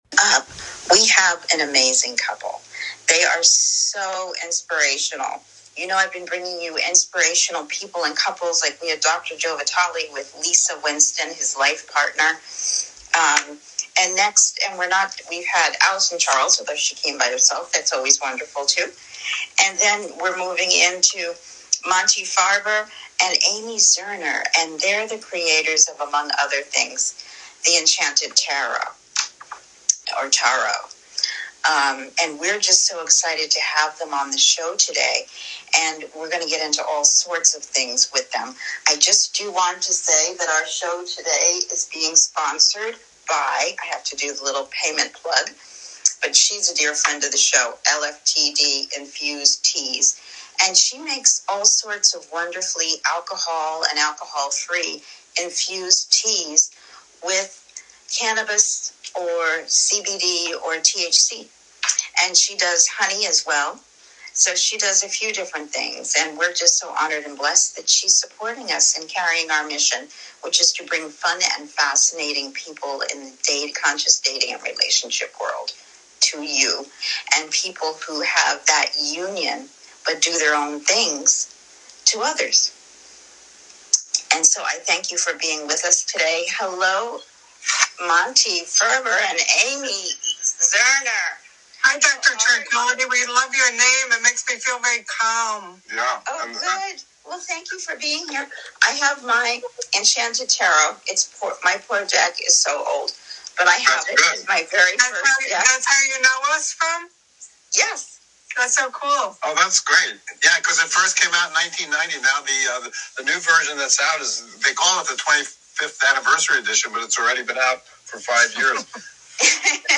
Each episode dives into meaningful conversations about their lives, passions, and how they embrace intentional living. Through thoughtful dialogue, the show invites listeners to explore what it means to live with purpose, balance, and authenticity in a fast-paced world.”